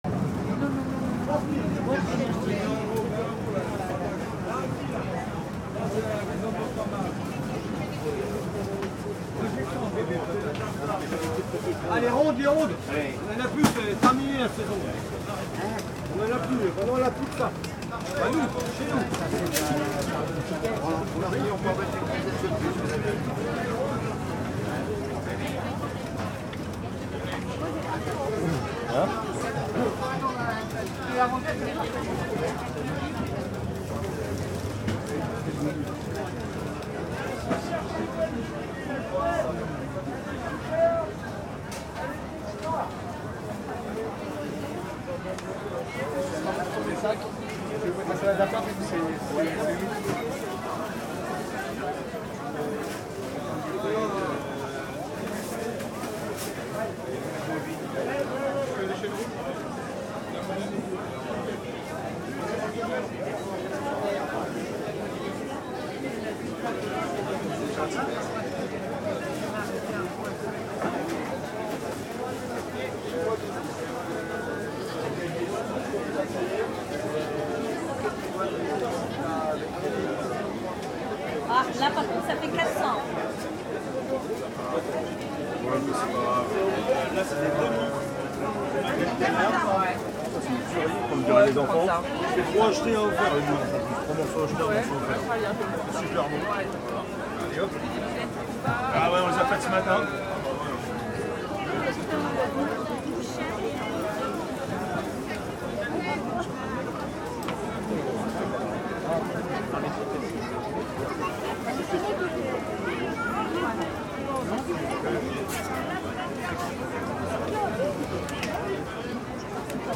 Market day in Neuilly-sur-Seine. People are speaking French, and I'm staring.
Listen : Outdoor Market #5 (322 s)